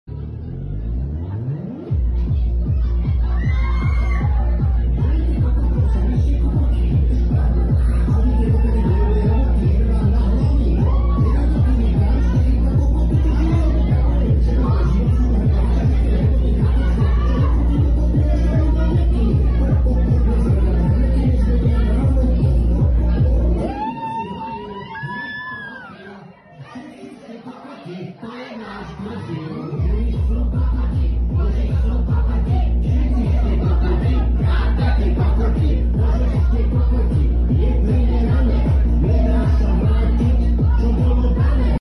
Halloween party